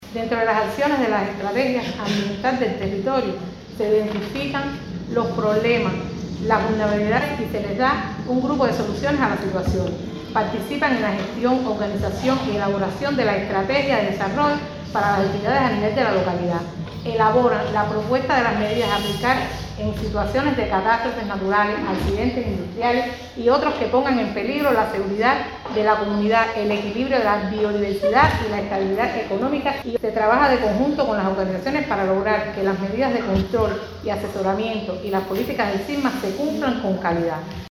PEDRO BETANCOURT.- A la salvaguarda del medio ambiente y la responsabilidad de los seres humanos en esta tarea se dedicó un ameno conversatorio efectuado en la biblioteca Manuel Navarro Luna, de este municipio.